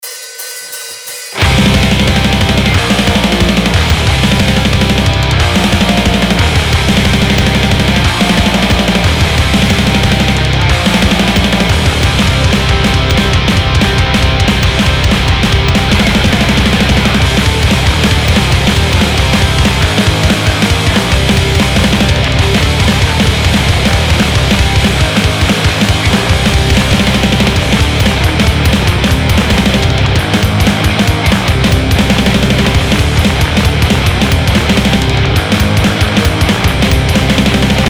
������� neocrust/d-beat ��������,��� �� ����� �����?